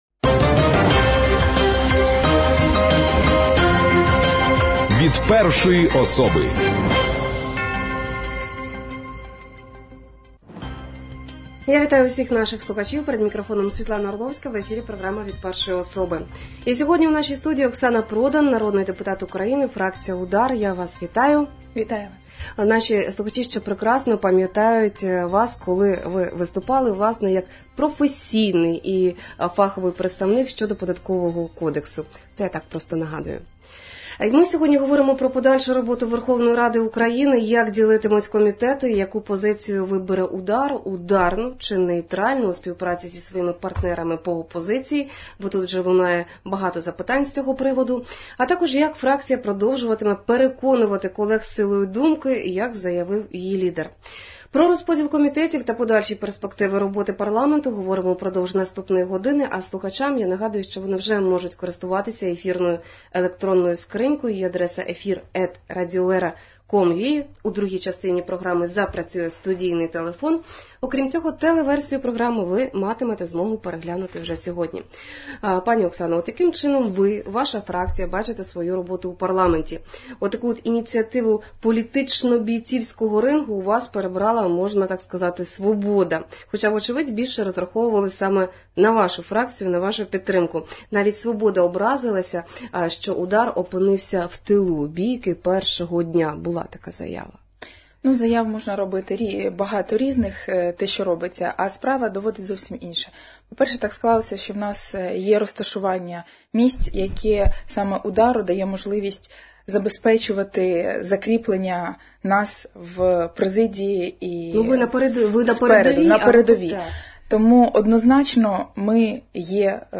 Про подальшу роботу Верховної Ради України, розподіл комітетів між фракціями, позицію партії “Удар” – “ударну”, нейтралітету чи співпраці зі своїми партнерами по опозиції – розмова в студії з Оксаною Продан, народним депутатом України, фракція «Удар».